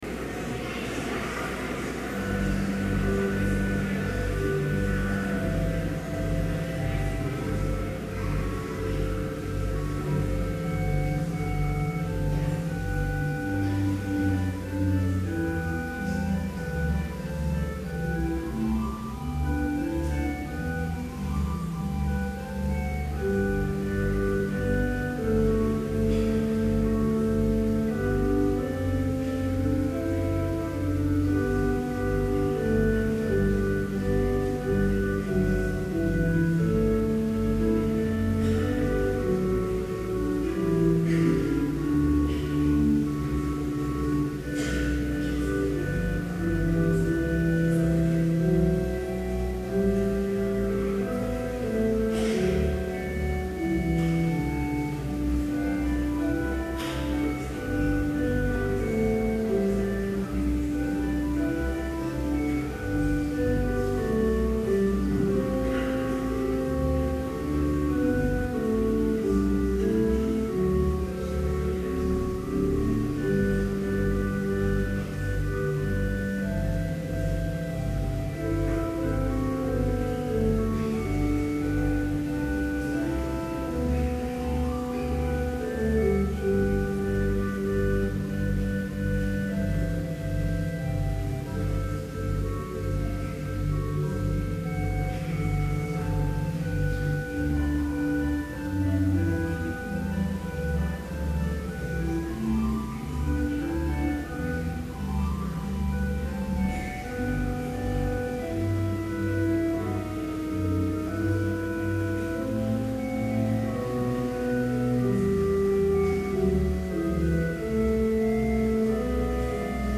Commencement Vespers worship service held on May 12
BLC Trinity Chapel, Mankato, Minnesota
Complete service audio for Commencment Vespers - May 12, 2011